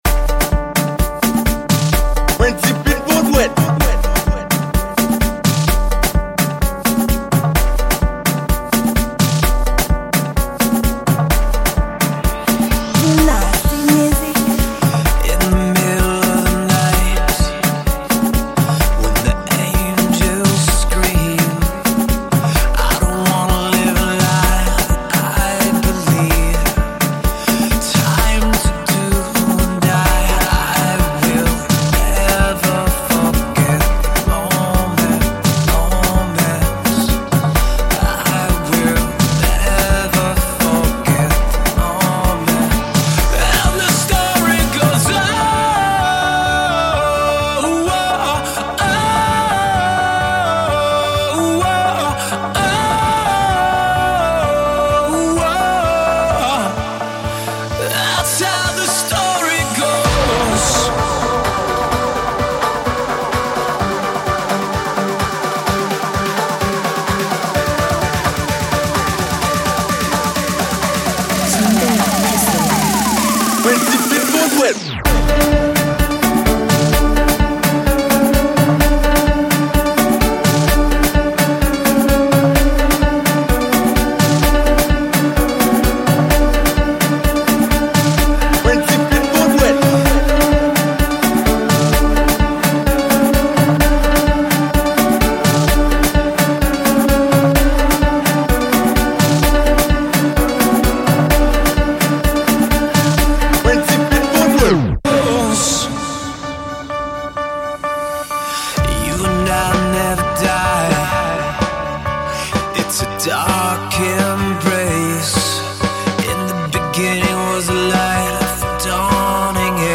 Afrhouse Music
Genre: Mix.